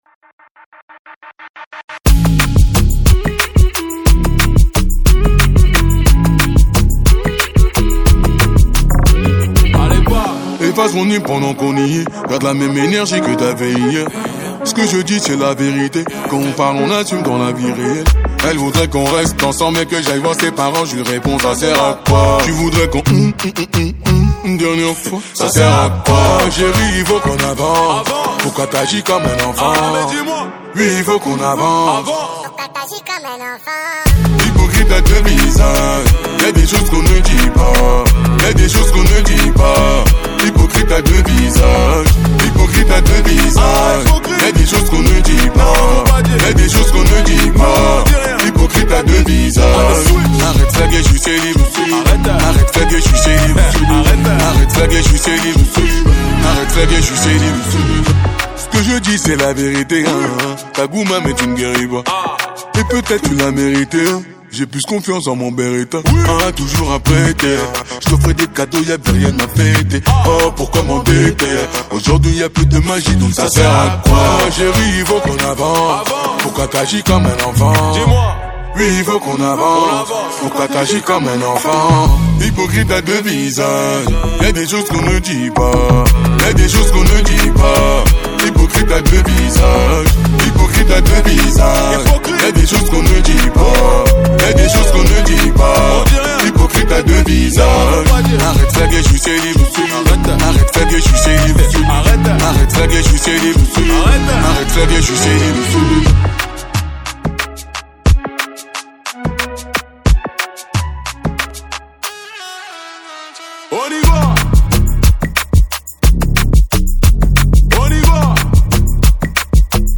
| Afro pop